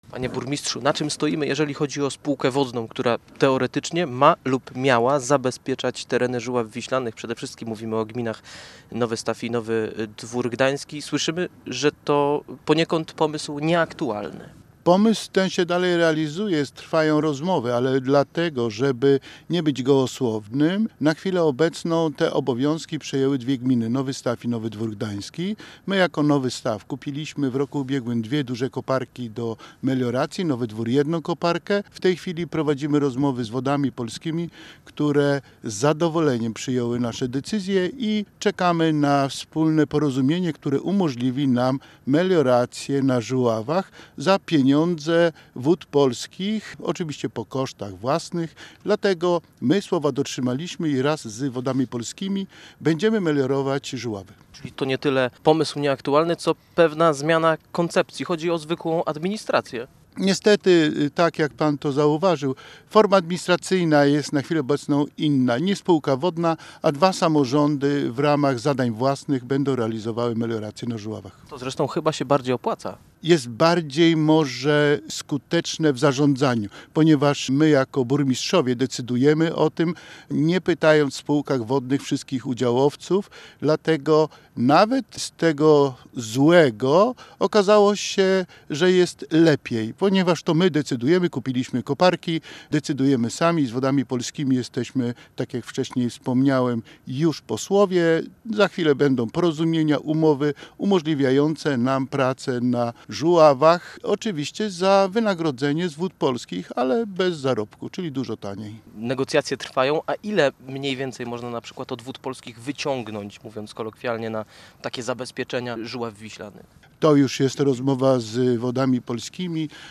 – Natrafiliśmy na problemy natury prawnej – przyznaje inicjator projektu Jerzy Szałach, burmistrz Nowego Stawu.